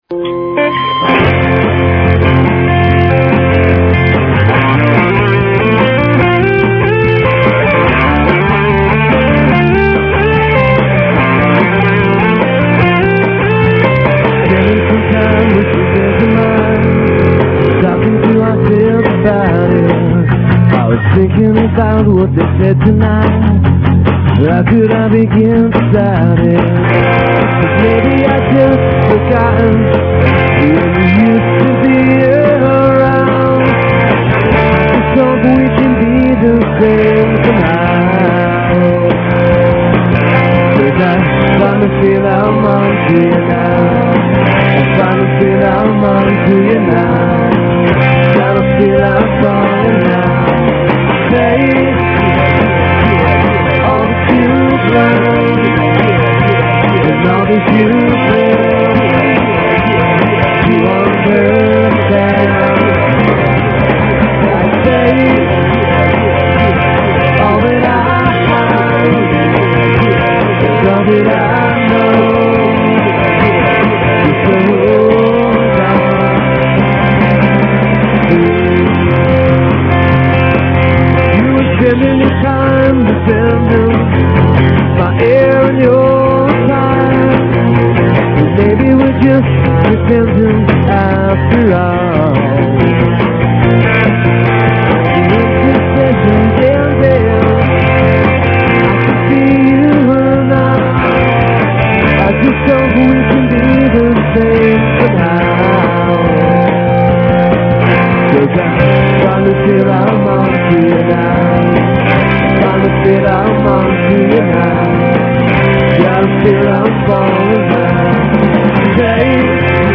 mit boston feb 2003